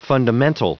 Prononciation du mot fundamental en anglais (fichier audio)
Prononciation du mot : fundamental